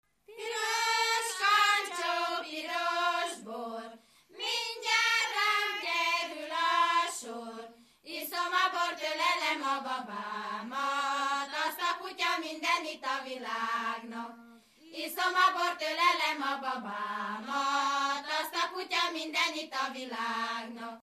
Erdély - Kolozs vm. - Kispetri
Előadó: Lányok, ének
Stílus: 6. Duda-kanász mulattató stílus
Szótagszám: 7.7.11.11
Kadencia: 1 (1) b3 1